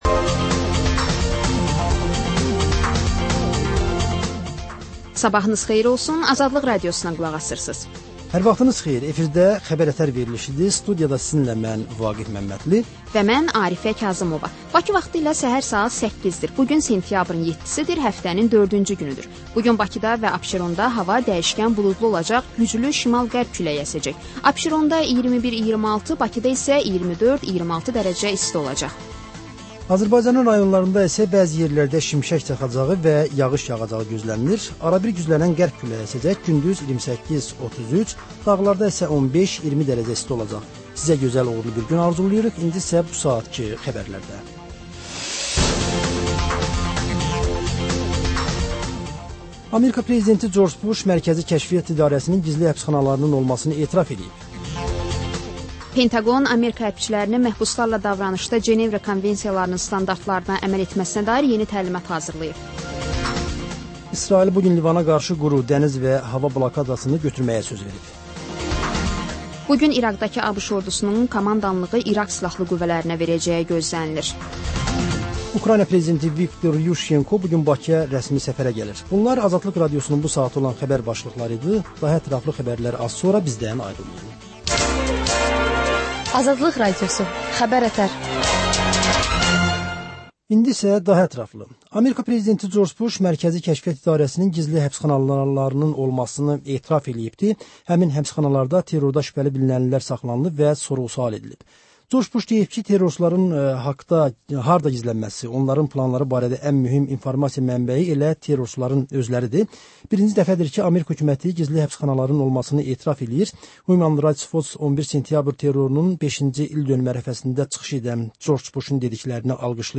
Bugün nələr gözlənir, nələr baş verib? Xəbər, reportaj, müsahibə.